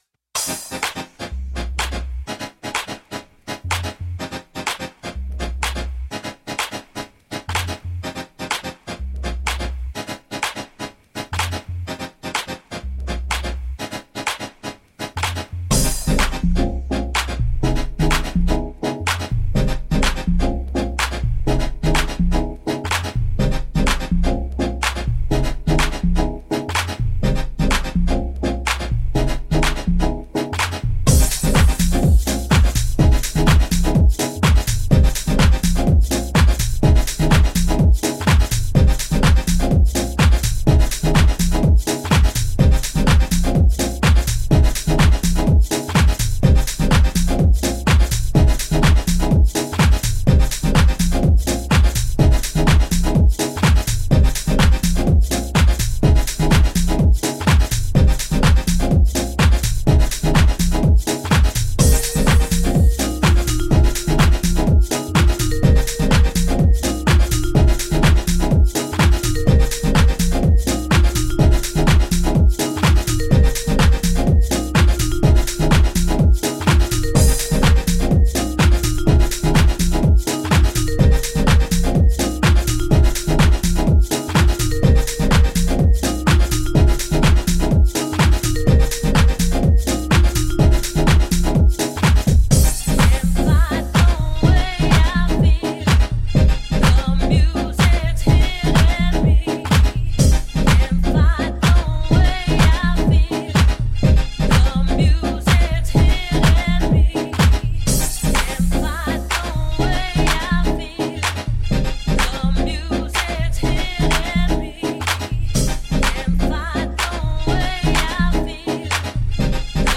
US-style deep house tracks